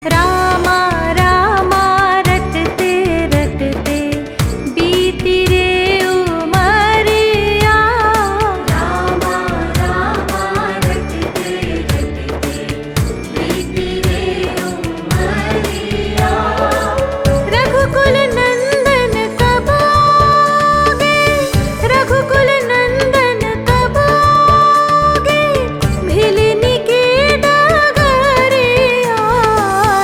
Bhajan Ringtone